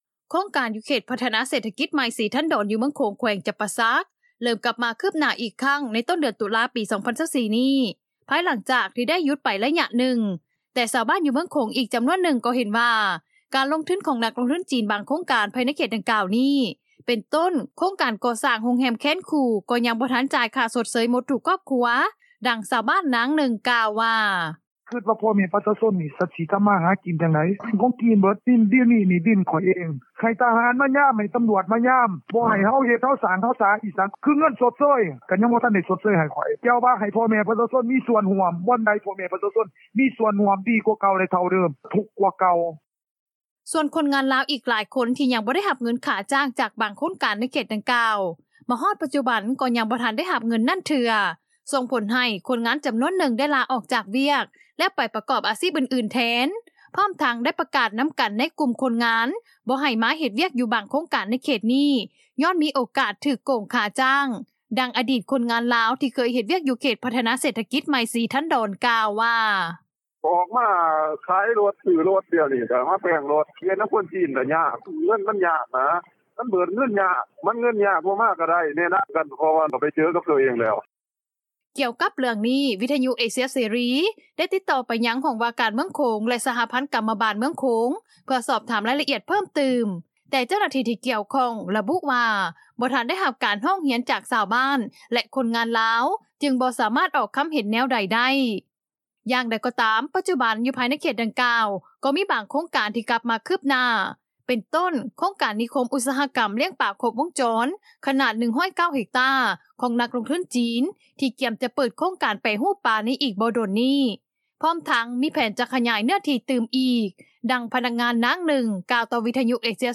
ໂຄງການຢູ່ເຂດພັດທະນາເສດຖະກິດໃໝ່ ສີທັນດອນ ຢູ່ເມືອງໂຂງຈໍາໂຂງ ແຂວງຈໍາປາສັກ ເລີ່ມກັບມາຄືບໜ້າອີກຄັ້ງ ໃນຕົ້ນເດືອນຕຸລາ ປີ 2024 ນີ້ ພາຍຫຼັງຈາກ ທີ່ໄດ້ຢຸດໄປໄລຍະໜຶ່ງ ແຕ່ຊາວບ້ານ ຢູ່ເມືອງໂຂງ ອີກຈໍານວນໜຶ່ງ ກໍ່ເຫັນວ່າ ການລົງທຶນຂອງນັກລົງທຶນຈີນ ບາງໂຄງການ ພາຍໃນເຂດດັ່ງກ່າວນີ້ ເປັນຕົ້ນ ໂຄງການກໍ່ສ້າງໂຮງແຮມແຄນຄູ່ ກໍ່ຍັງບໍ່ທັນຈ່າຍຄ່າຊົດເຊີຍໝົດໃຫ້ທຸກຄອບຄົວ, ດັ່ງຊາວບ້ານ ນາງໜຶ່ງ ກ່າວວ່າ:
ສ່ວນຄົນງານລາວ ອີກຫຼາຍຄົນ ທີ່ຍັງບໍ່ໄດ້ຮັບເງິນຄ່າຈ້າງ ຈາກບາງໂຄງການໃນເຂດດັ່ງກ່າວ ມາຮອດປັດຈຸບັນ ກໍ່ຍັບໍ່ທັນໄດ້ຮັບເງິນນັ້ນເທື່ອ ສົ່ງຜົນໃຫ້ ຄົນງານຈໍານວນໜຶ່ງ ໄດ້ລາອອກຈາກວຽກ ແລ້ວໄປປະກອບອາຊີບອື່ນໆແທນ ພ້ອມທັງໄດ້ປະກາດນໍາກັນ ໃນກຸ່ມຄົນງານ ບໍ່ໃຫ້ມາເຮັດວຽກຢູ່ບາງໂຄງການ ໃນເຂດນີ້ ຍ້ອນມີໂອກາດຖືກໂກງຄ່າຈ້າງ, ດັ່ງອະດີດຄົນງານລາວ ທີ່ເຄີຍເຮັດວຽກ ຢູ່ເຂດພັດທະນາເສດຖະກິດໃໝ່ ສີທັນດອນ ກ່າວວ່າ: